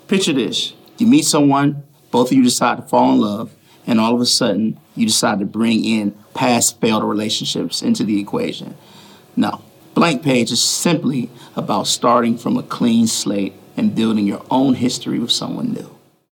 Audio / The War And Treaty's Michael Trotter Jr. talks about their Grammy-nominated song, "Blank Page."